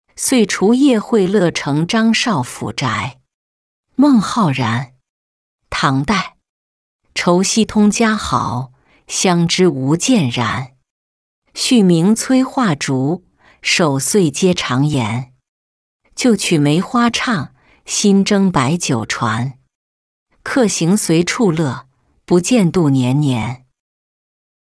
岁除夜会乐城张少府宅-音频朗读